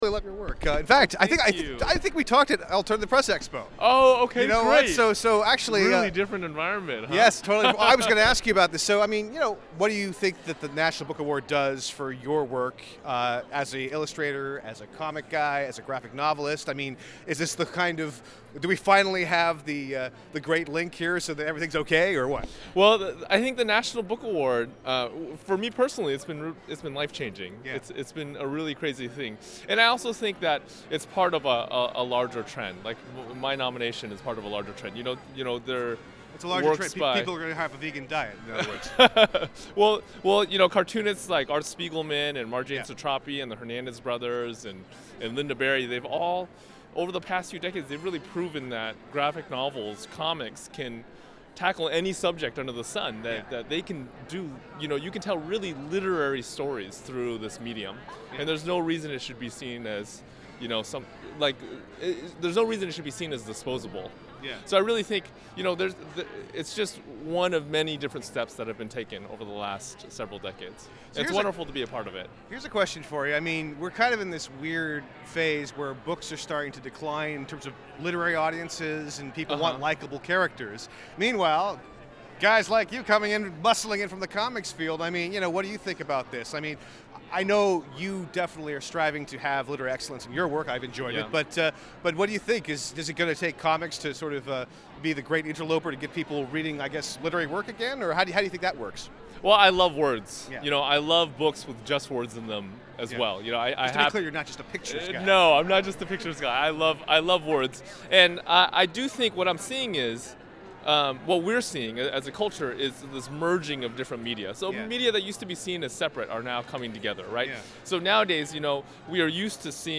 Gene Luen Yang is nominated for the Young People’s Literature Award in this year’s National Book Awards. I ran into Yang on the floor before the National Book Awards and chatted with him for about three minutes about the intersection between comics and literary work.